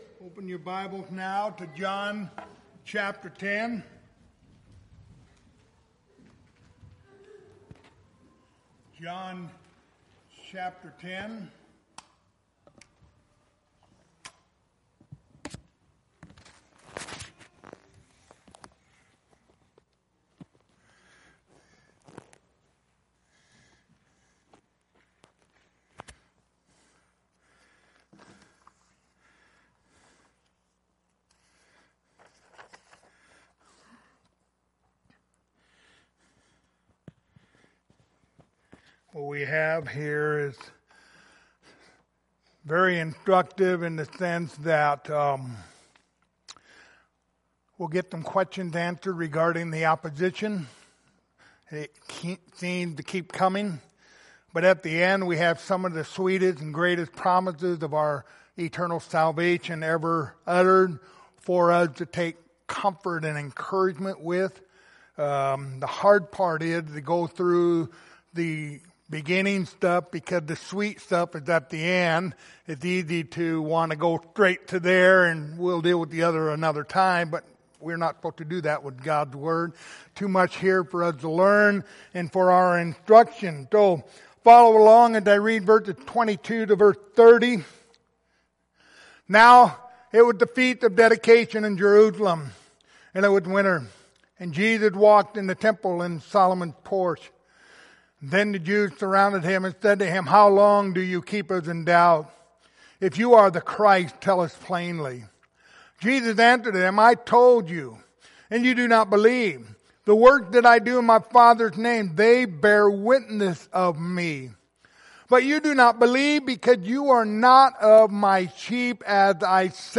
Passage: John 10:22-30 Service Type: Wednesday Evening